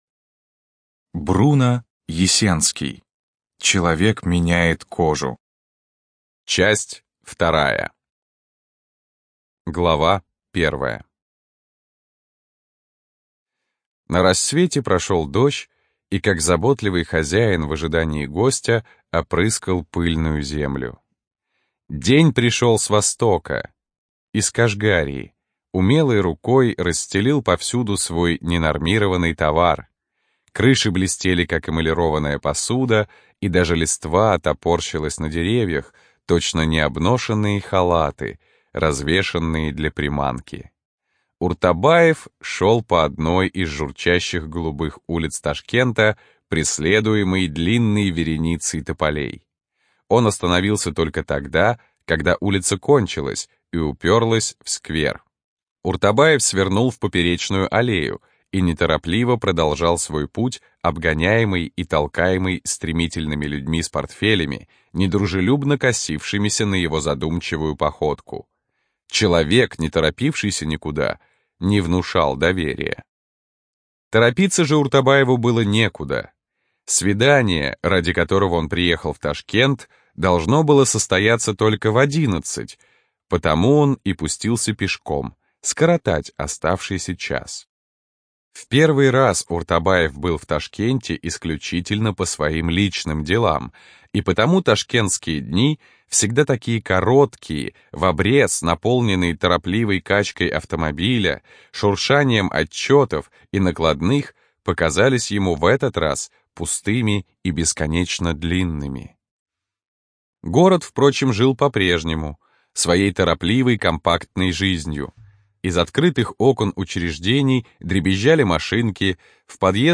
Предлагаем послушать вторую часть романа, прочитанного по тексту упомянутого издания 1935 года, ещё не тронутому цензурой и не заредактированному.